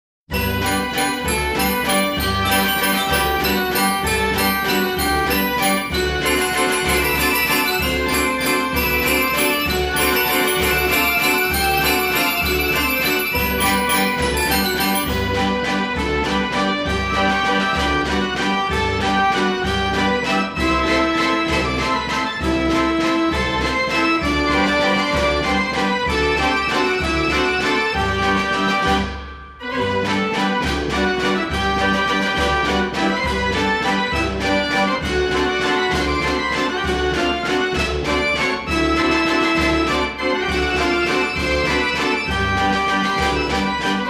Accompaniment:-9 Stopped Flute Pipes; 18 Violin Pipes.
Traps:- Bass Drum, Snare Drum, and Cymbal.